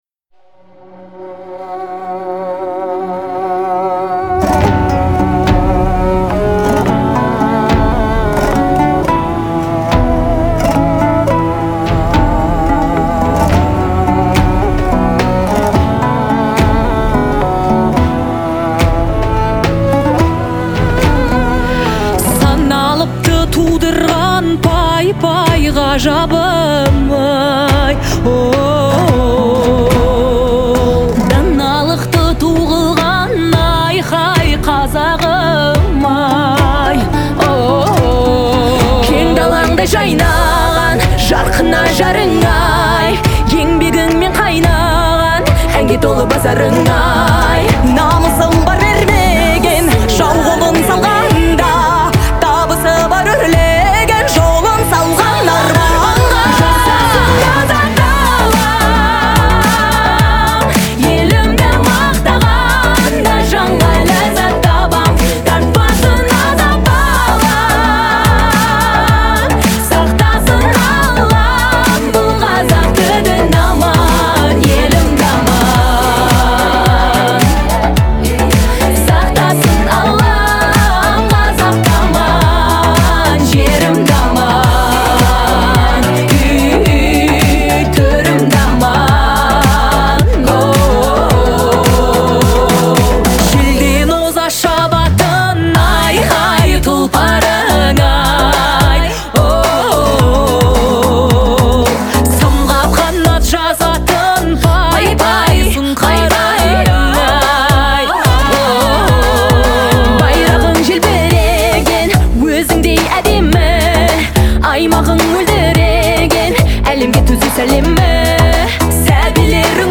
это патриотическая песня в жанре поп